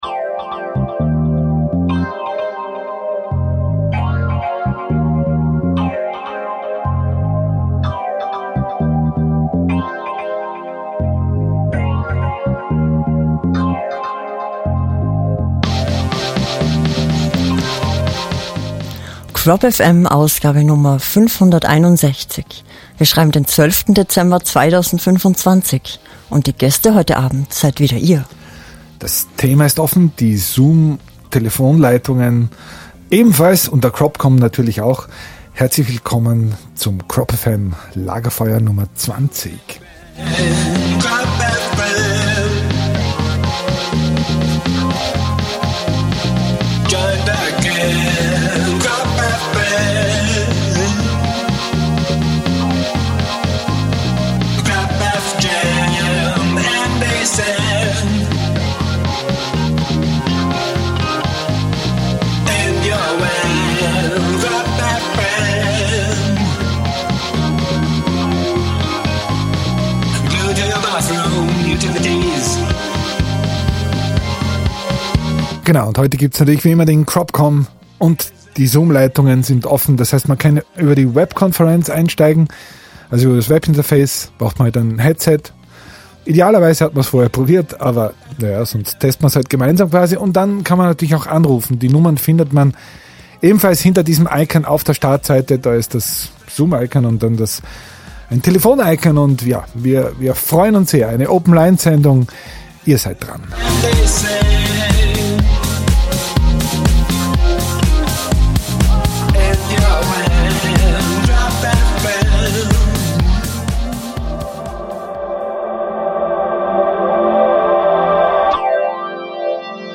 stehen bzw. sitzen bereit im Studio